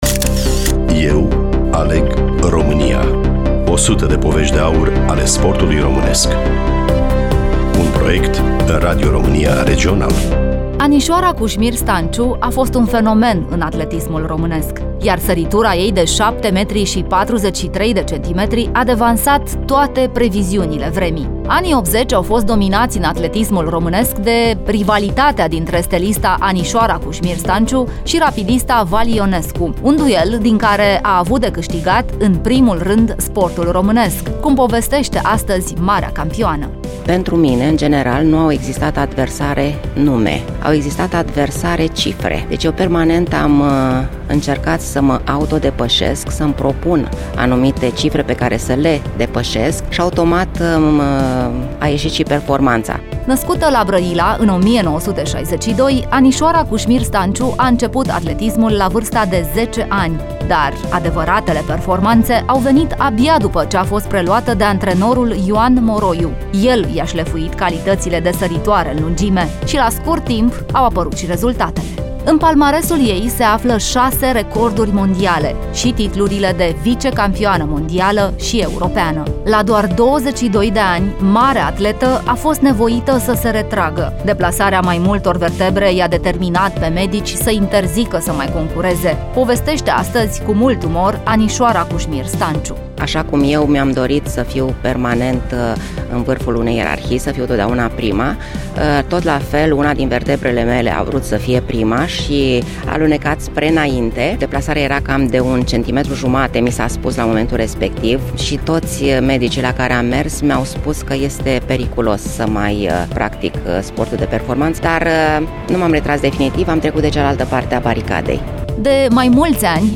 Studioul: Radio România Constanța